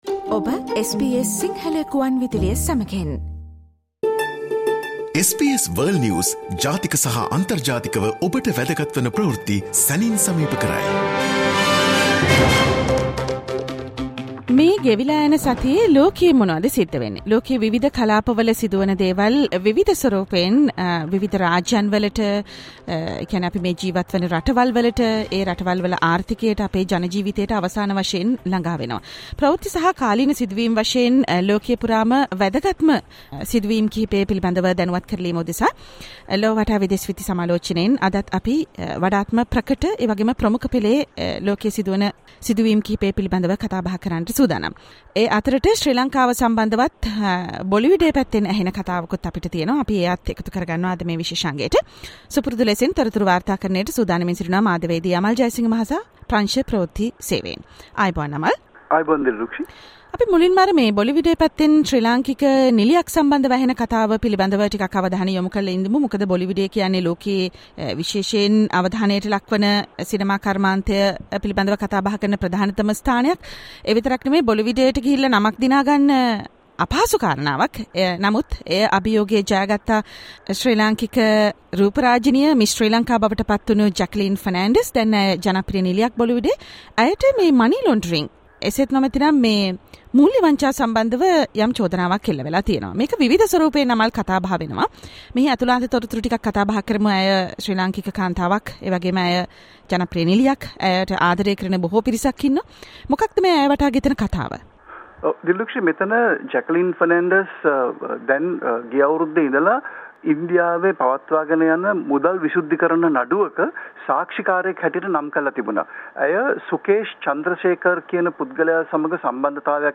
World's prominent news highlights in a few minutes - listen to the SBS Sinhala Radio weekly world News wrap every Friday Share